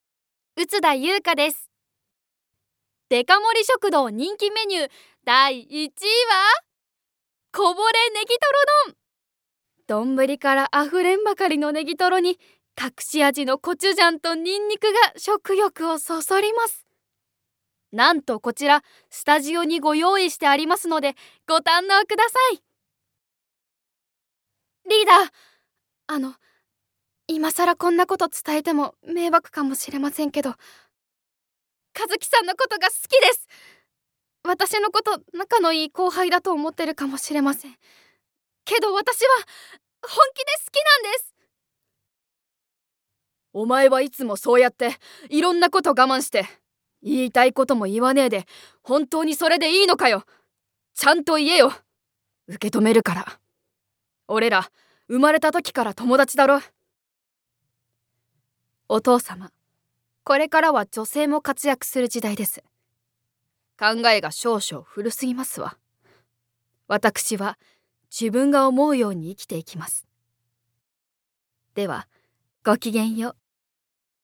One shot Voice（サンプルボイスの視聴）